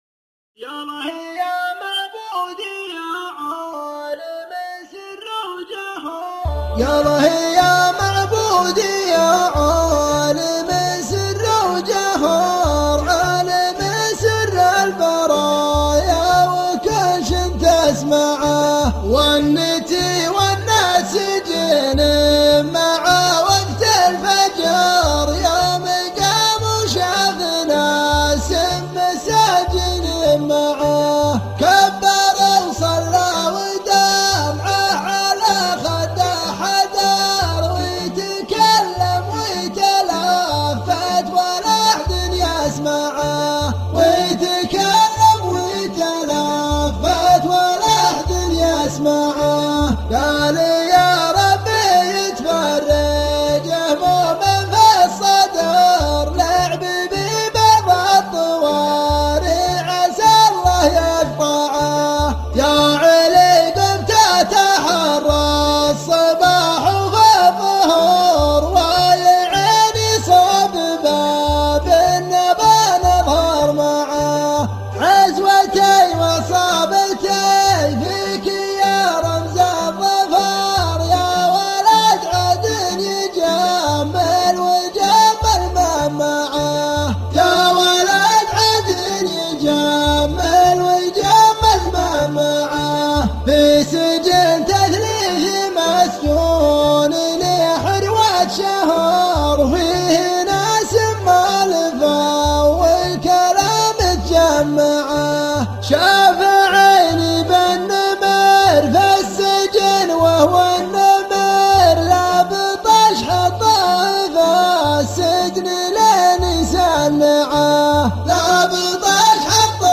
الشيلات -> شيلات منوعه 7